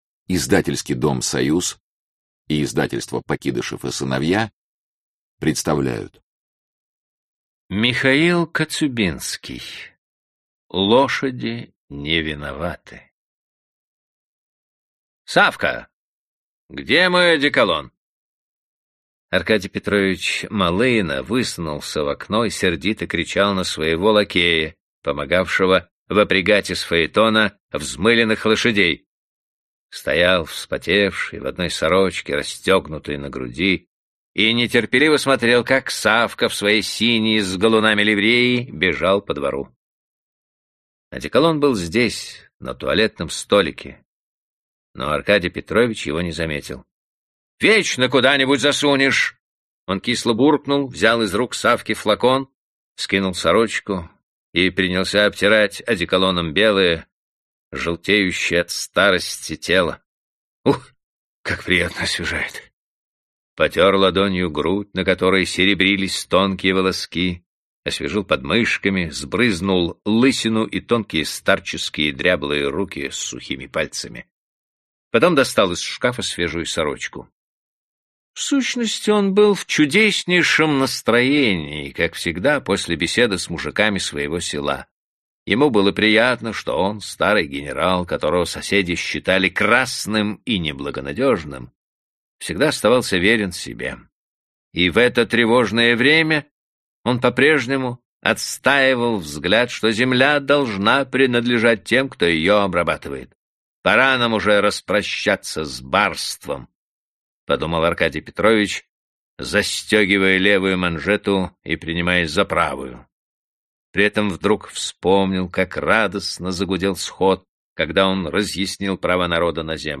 Аудиокнига Лошади не виноваты | Библиотека аудиокниг